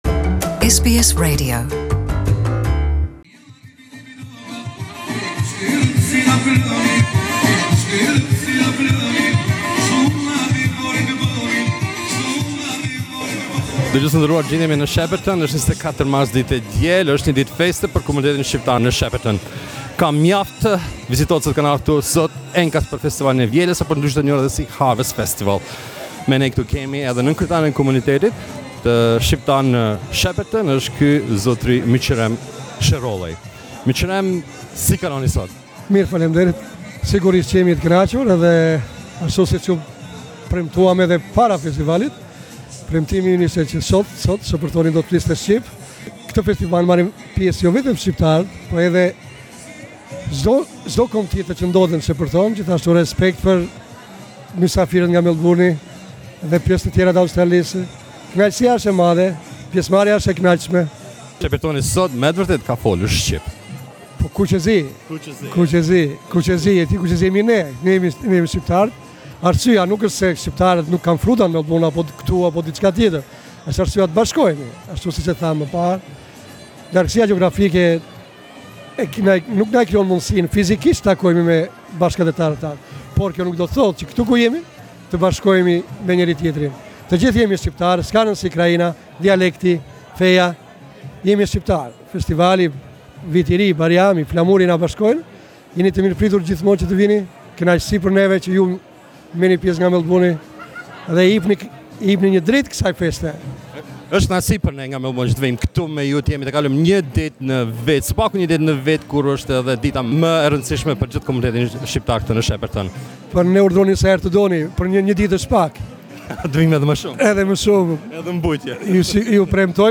We spoke with him during the festival.